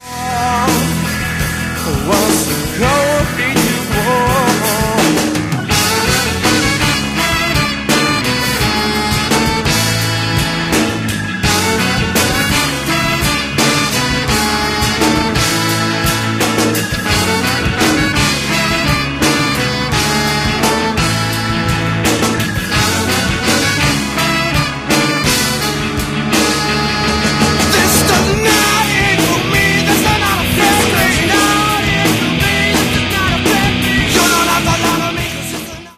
36 second reduced quality OGG sample